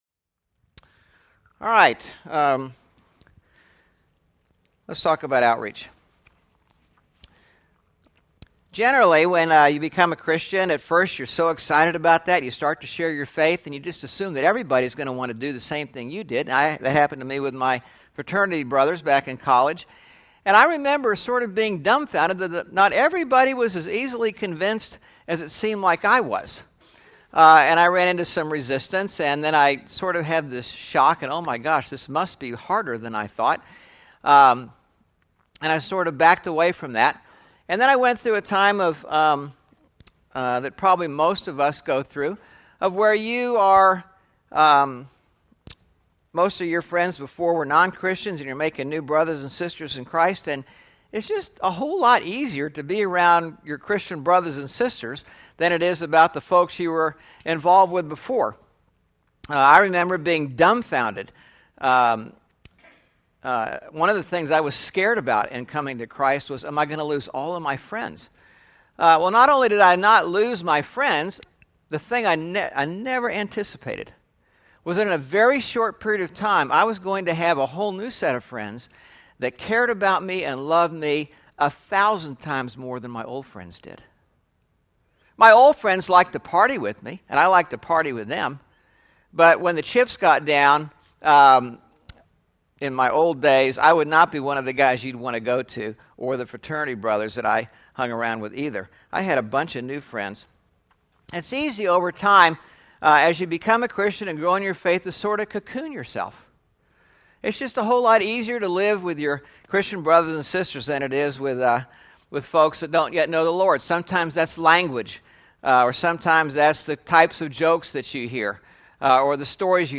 4/10/11 Sermon (Saving Private Ryan part 3) – Church in Irvine, CA – Pacific Church of Irvine